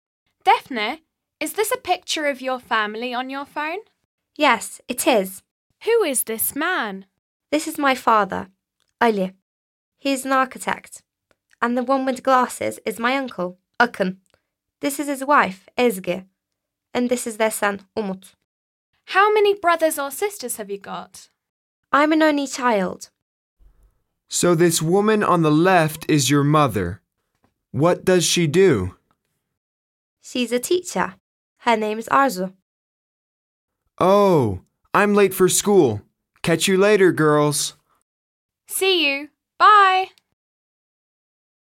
B. Listen to Defne and her friends, and answer the questions